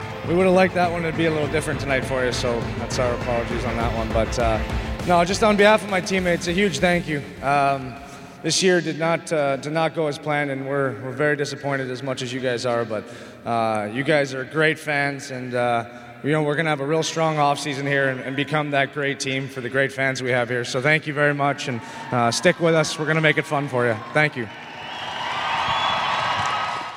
Nick Foligno speaks to the fans